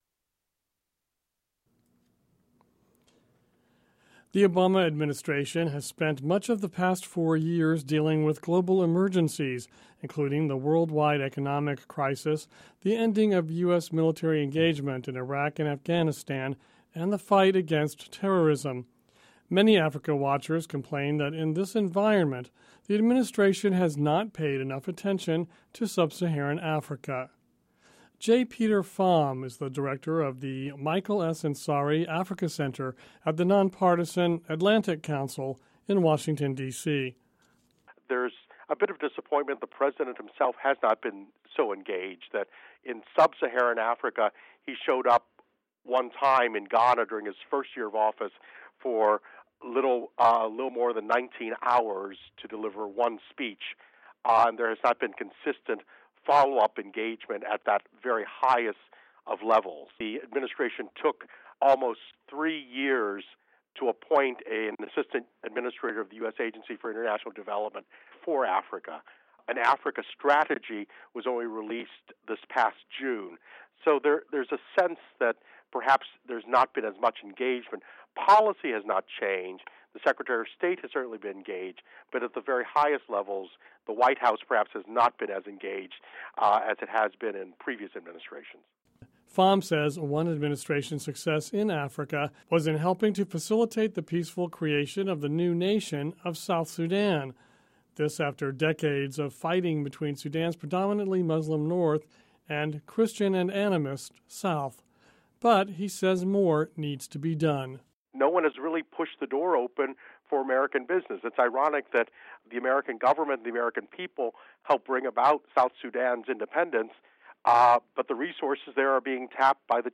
Listen to report on US policy towards Africa